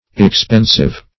Expensive \Ex*pen"sive\, a.